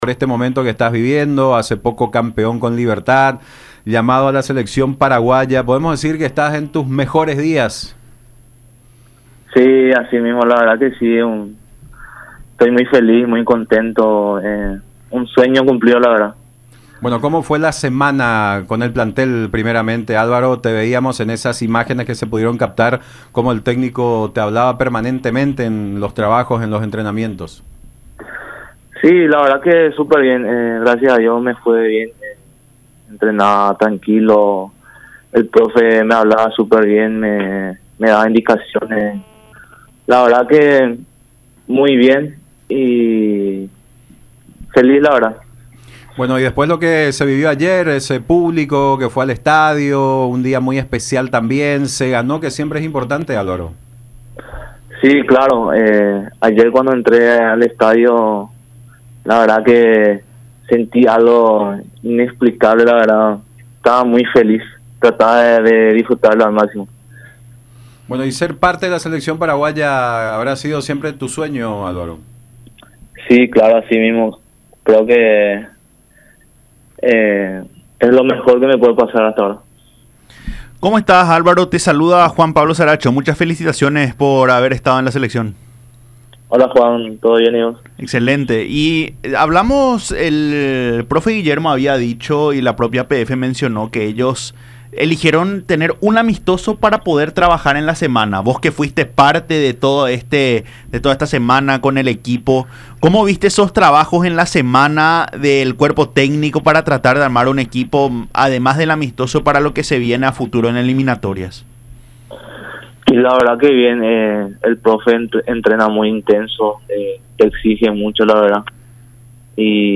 “Estoy muy feliz, muy contento, un sueño cumplido la verdad. Entrené tranquilo, recibía indicaciones del profe, fue una semana muy especial y más por el debut”, refirió a Fútbol Club, por radio la Unión y Unión TV.
“Al ver el estadio lleno ayer y cuando entre fue un momento inexplicable. Este llamado de la selección es lo mejor que me pasó hasta ahora en mi carrera”, siguió relatando muy emocionado.